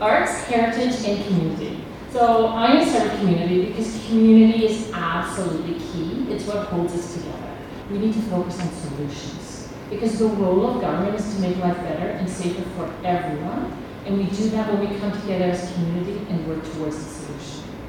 VALLEY VOTES: Algonquin-Renfrew-Pembroke federal candidates debate at Festival Hall L’Equinox a success